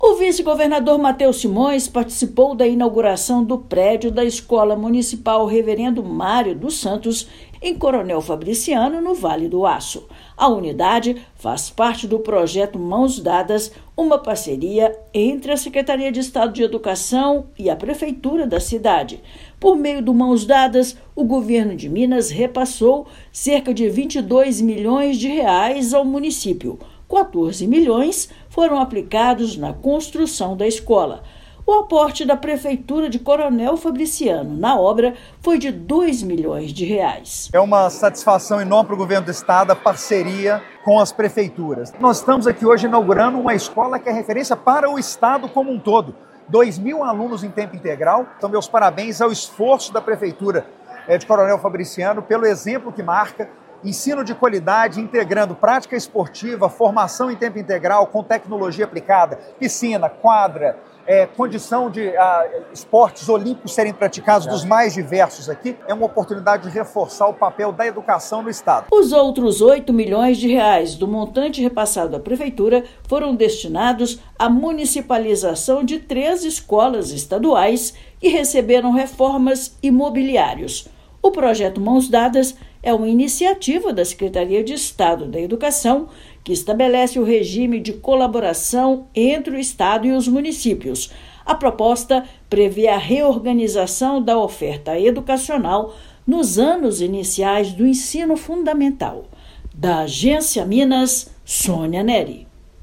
Estado investiu R$ 22 milhões no município para entregar unidade que pode atender mais de 1,9 mil estudantes da cidade. Ouça matéria de rádio.